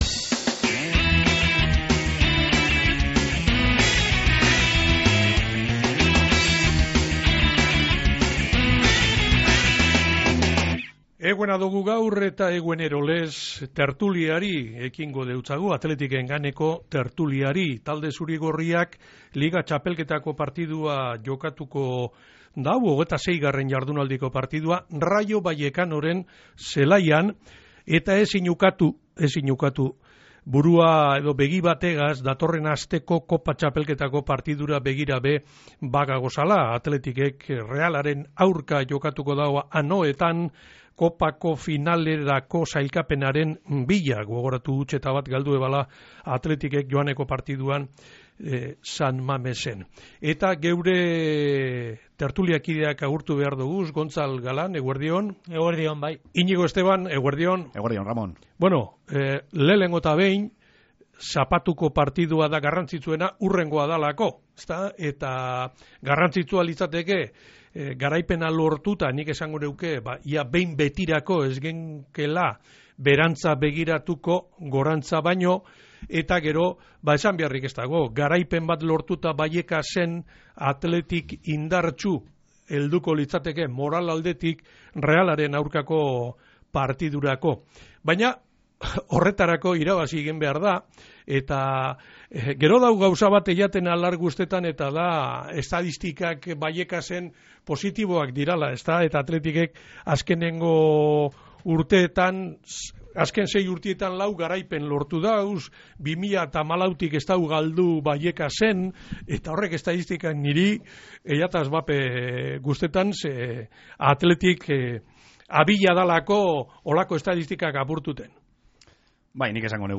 Athleticen tertulia | Bizkaia Irratia